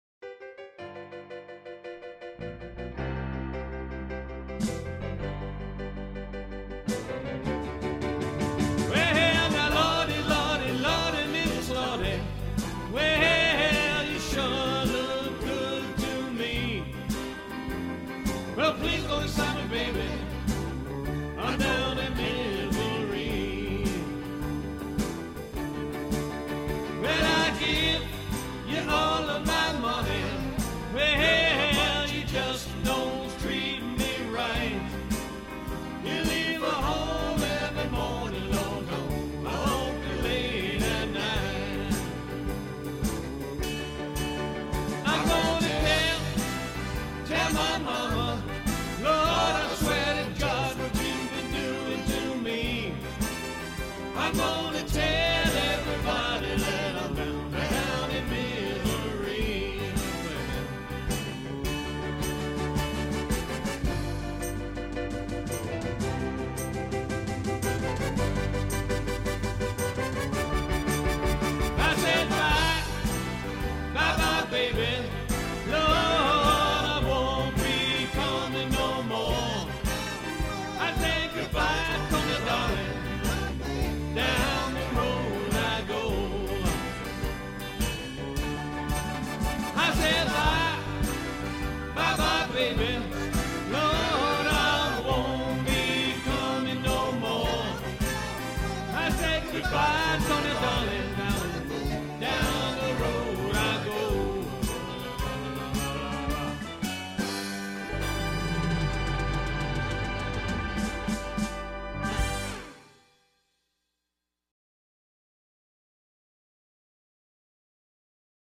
Rock & Roll